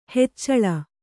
♪ heccaḷa